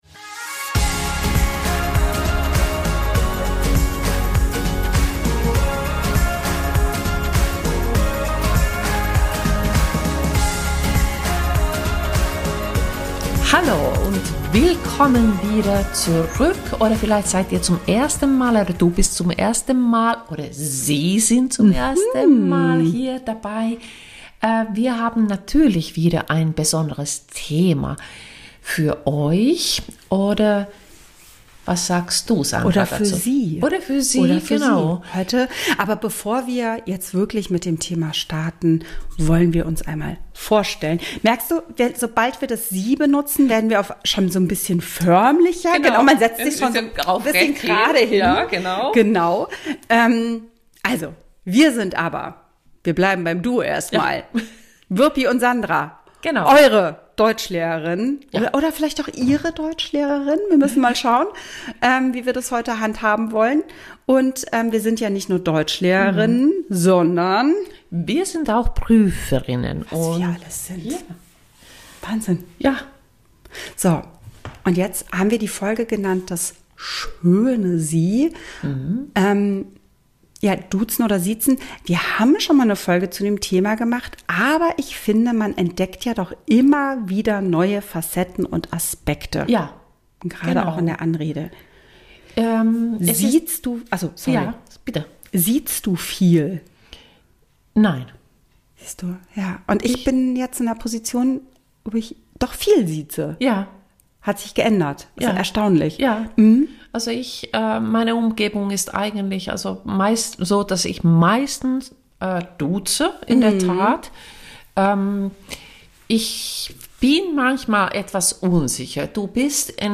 zwei zertifizierte Dozentinnen, Prüferinnen und unterrichten schon lange Deutsch als Fremd- und Zweitsprache. In jeder Woche präsentieren wir Euch eine neue Folge, in der wir über ein Thema sprechen und Euch an einigen Beispielen wichtige Inhalte der deutschen Grammatik vermitteln.